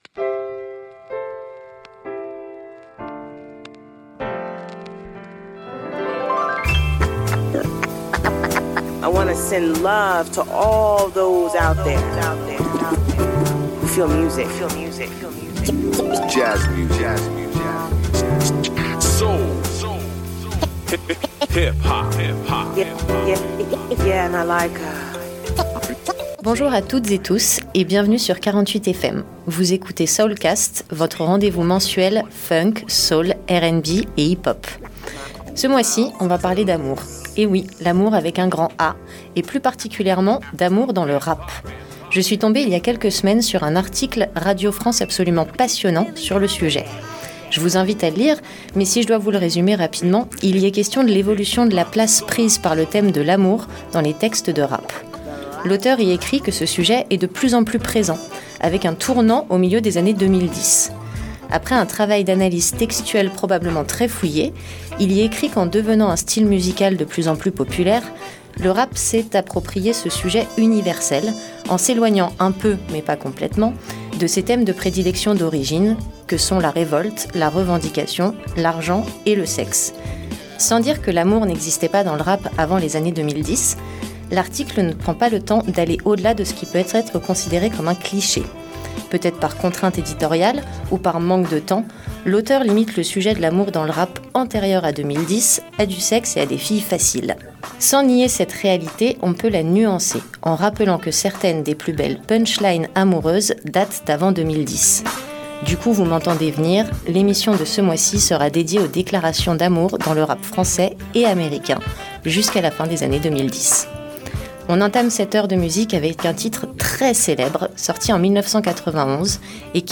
Emission du mardi 21 avril à 21 h 00